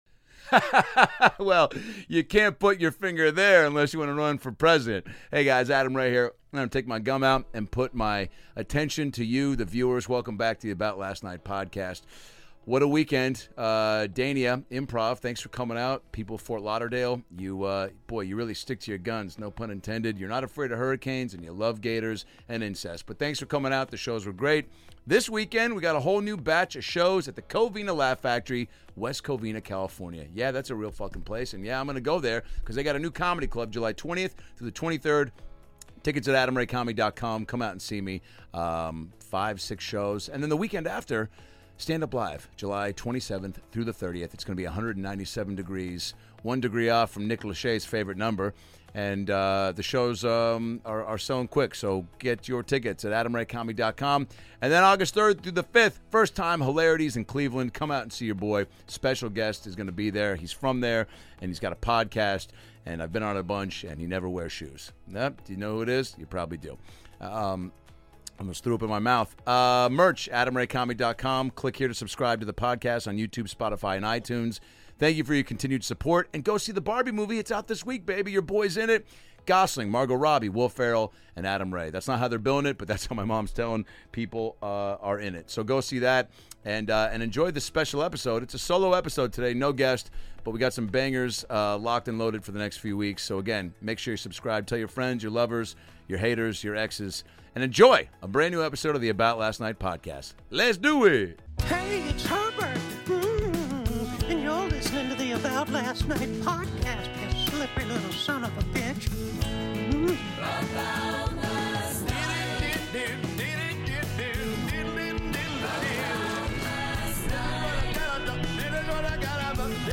Adam Ray is solo this episode and talks about being in the Barbie movie, going to the MLB All Star Game in Seattle and Tom Cruise's crazy stunts!